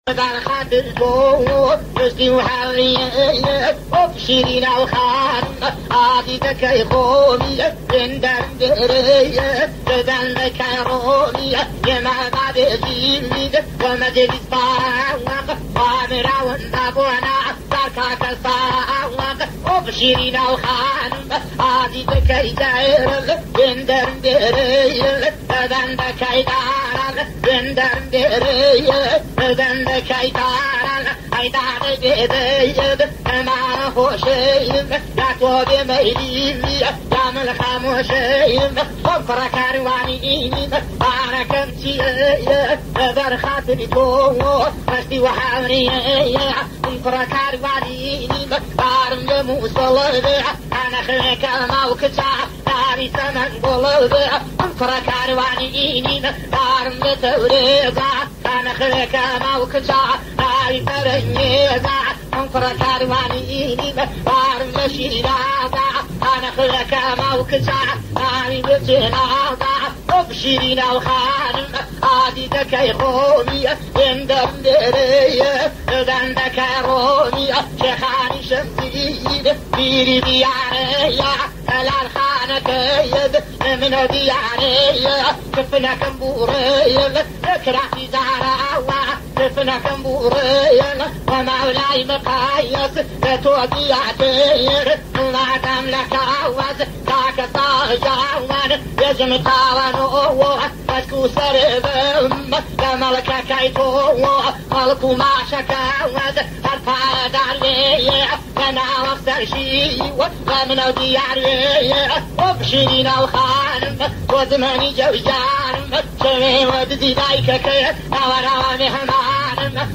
ژانر (سبک) : فولکلور
نوع ساز : بدون موسیقی(چه پله)